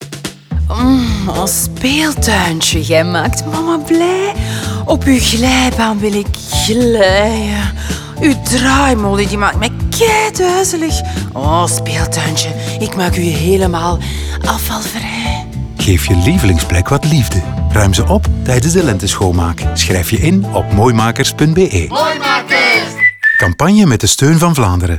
Die roept op om wat liefde te geven aan je lievelingsplek, door ze zwerfvuilvrij te maken tijdens de Lenteschoonmaak. De campagne bevat drie video’s, twee radiospots en een key visual die in allerlei media zal verschijnen.